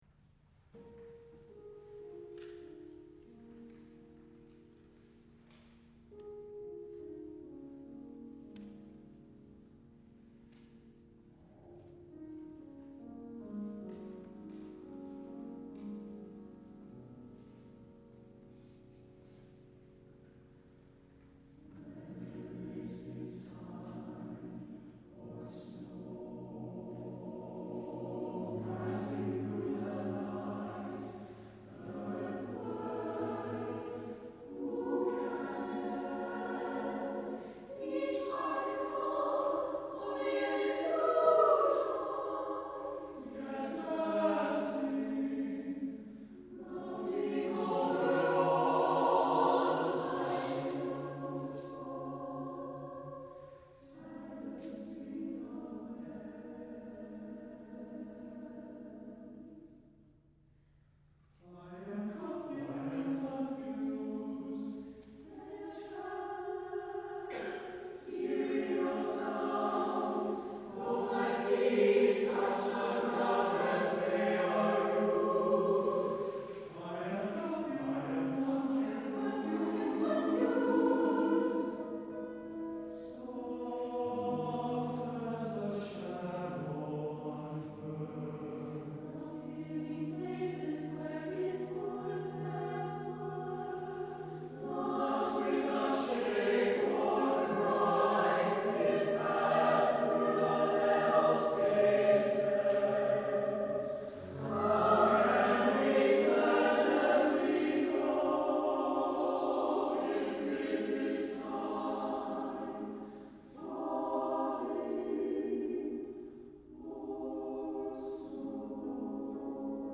for SATB Chorus and Piano (1998)